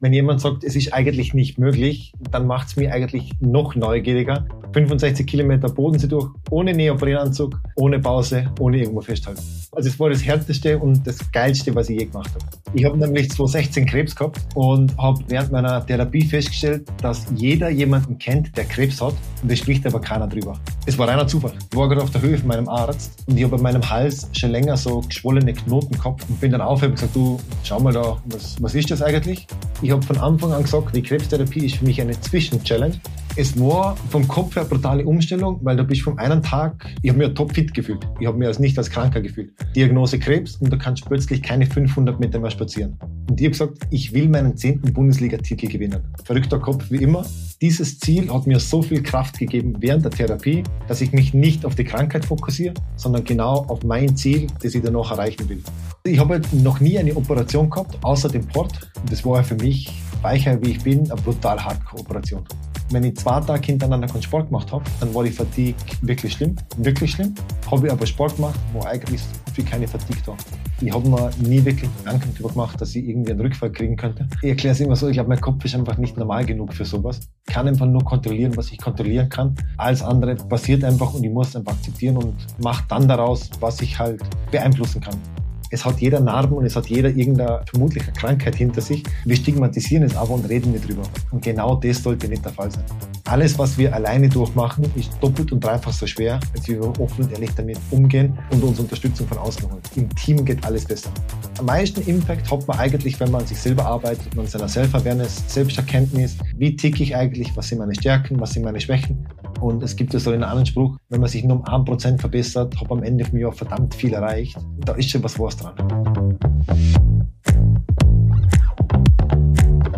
Gespräch) · Folge 23 ~ Männerkrebs – Was tut Mann mit Krebs?